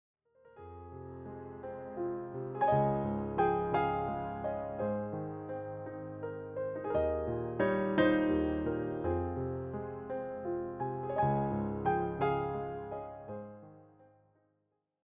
solo piano
Just calm and relaxing renditions of these well-known songs.